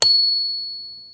question_markTermékkör Pengetős csengő
XLC DD-M02 pengetős réz csengő, arany színű
Mini csengő, erős pengető mechanikával